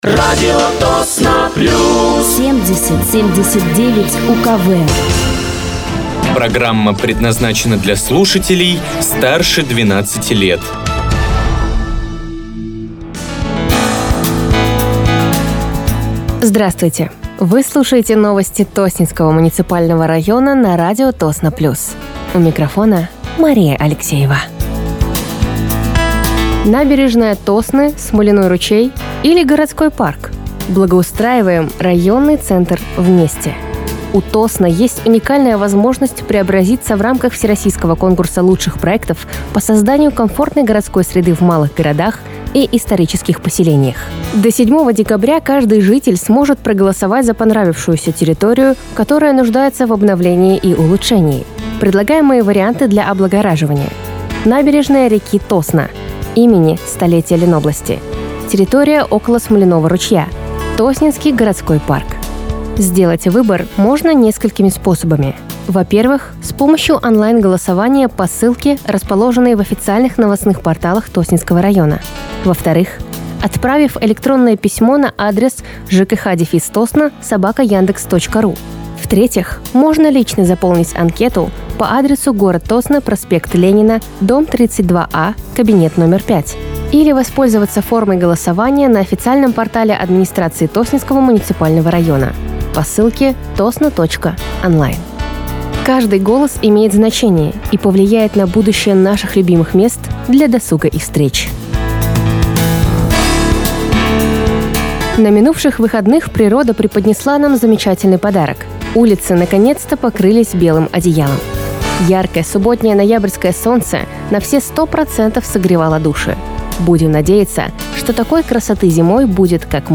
Выпуск новостей Тосненского муниципального района от 18.11.2025
Вы слушаете новости Тосненского муниципального района на радиоканале «Радио Тосно плюс».